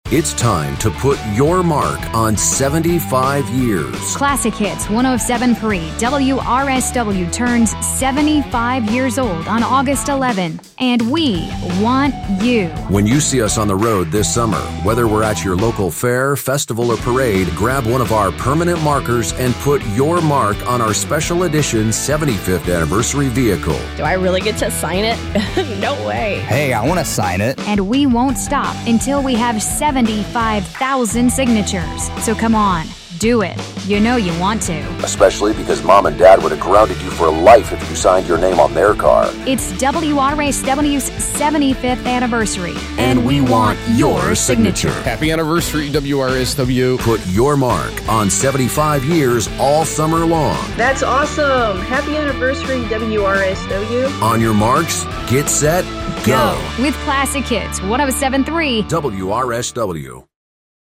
Best Radio Station Promotional CampaignWRSW-FM (Warsaw) – Put Your Mark on 75 Years!